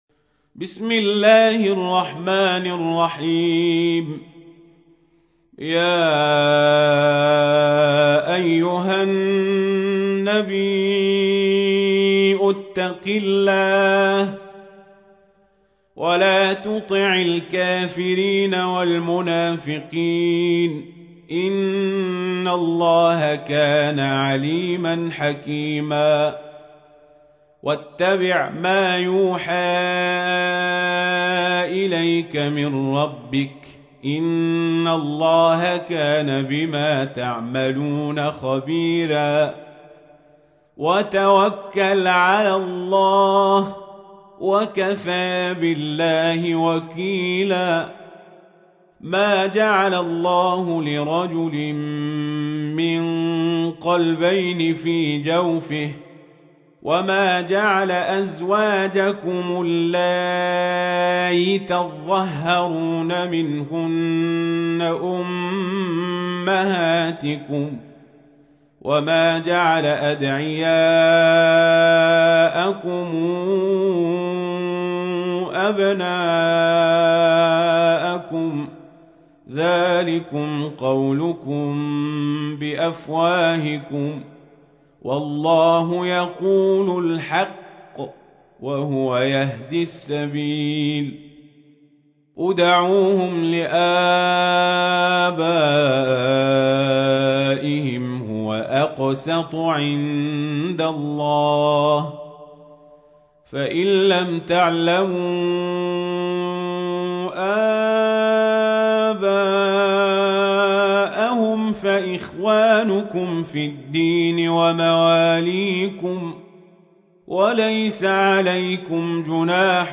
تحميل : 33. سورة الأحزاب / القارئ القزابري / القرآن الكريم / موقع يا حسين